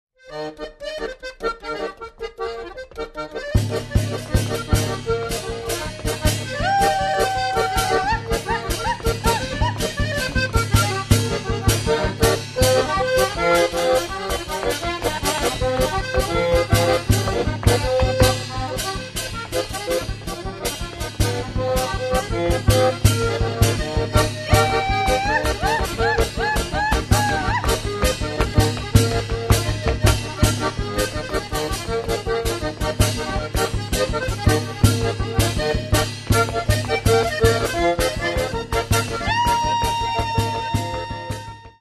Каталог -> Народная -> Инструментальная